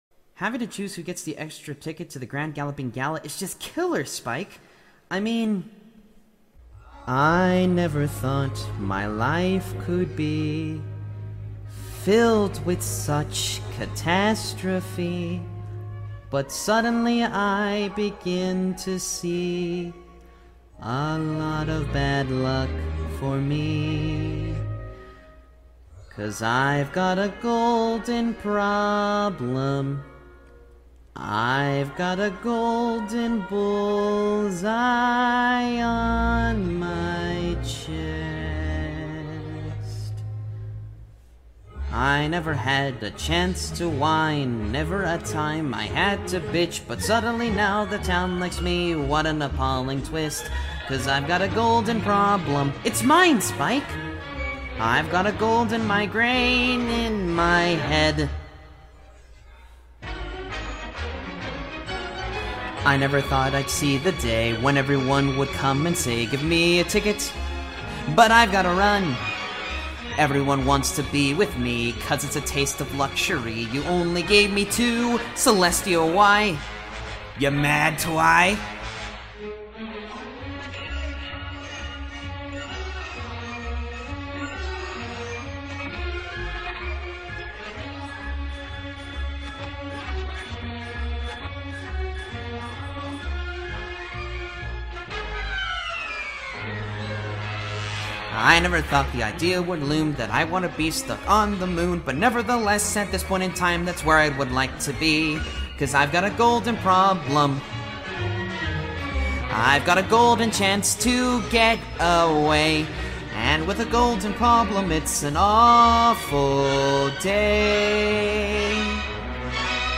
here's the full studio quality version